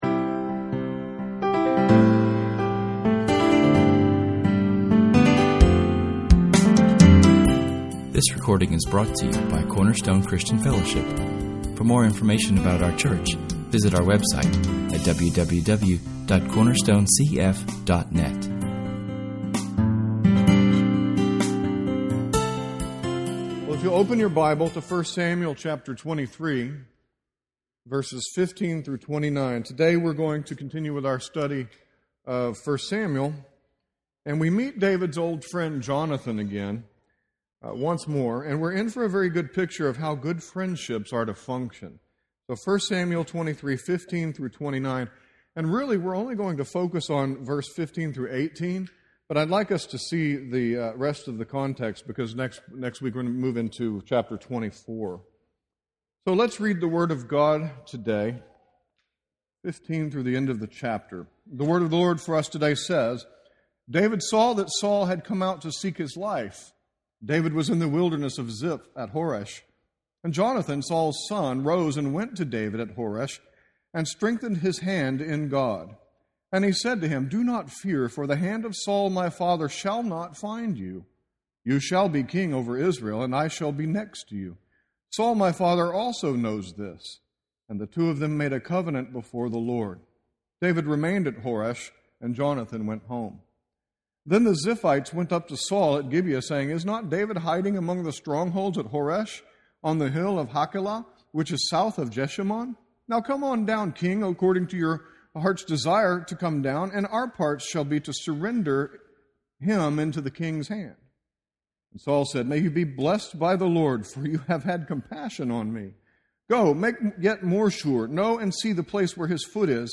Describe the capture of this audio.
[esvignore]Psalm 54[/esvignore] was our public reading.